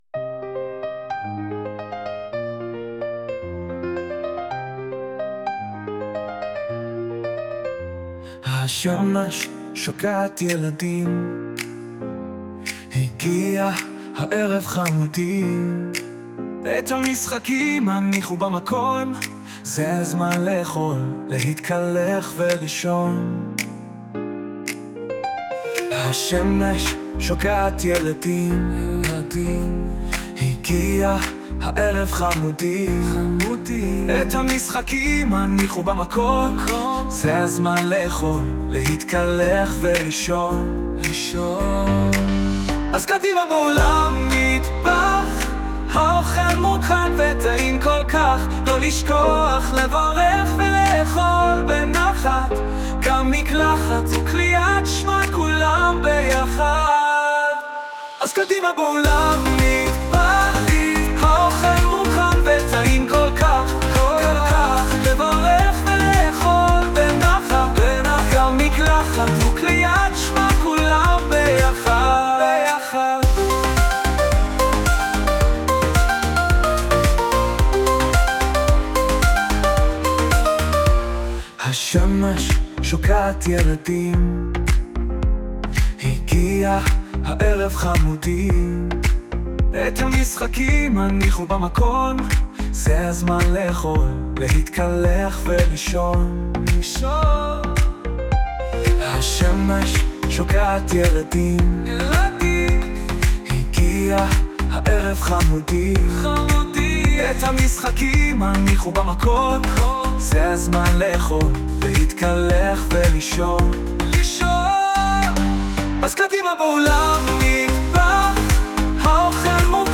Audio - שיתוף שירים שנוצרו ב- AI
ביקשתי שיר רגוע כיאה לזמן התארגנות לקראת שינה, בחרתי פסנתר קלאסי, נתתי הוראות לקולות שניים, וקיבלתי שיר שלרגעים נדמה כי הלב יוצא מעוצמת הרגש והתחנון מקולו של הזמר.